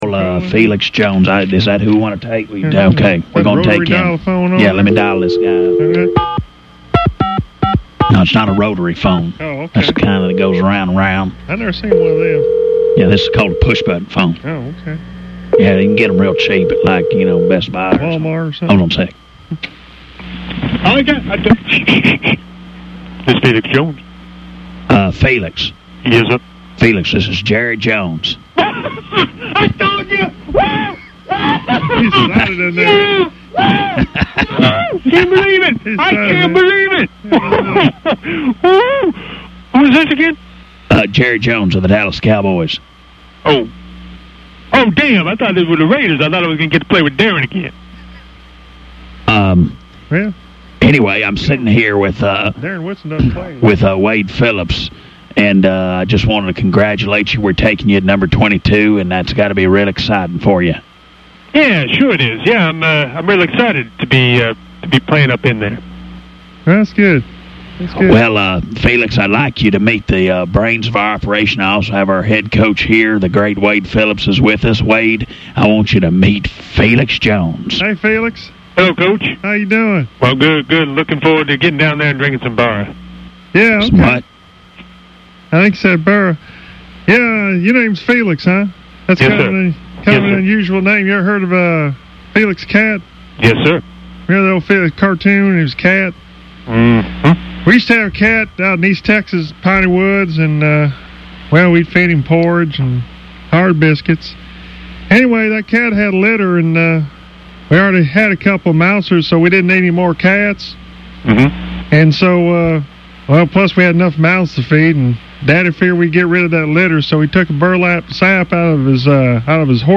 Fake Jerry Jones & Fake Wade Phillips Call Felix Jones - The UnTicket